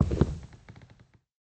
chore: wood sounds
mining5.ogg